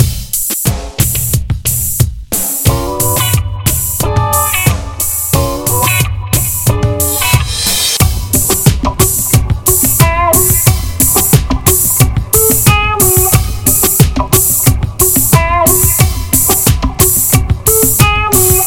funky - guitare - urbain - soul - cool